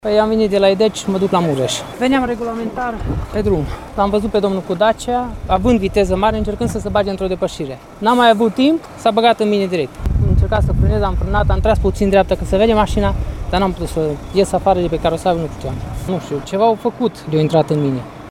Șoferul autocamionului spune că a încercat să evite impactul, însă nu a reușit: